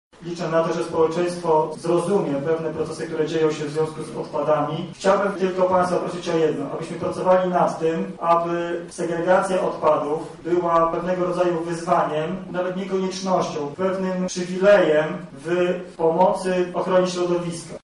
Konferencja obejmowała zarówno aspekty prawne, jak i praktyczne, czy finansowe.